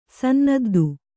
♪ sannaddu